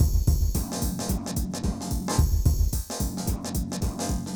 RemixedDrums_110BPM_46.wav